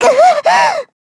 Yanne_L-Vox_Damage_kr_04.wav